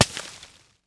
Christmas Sound Effects
015 snowball.wav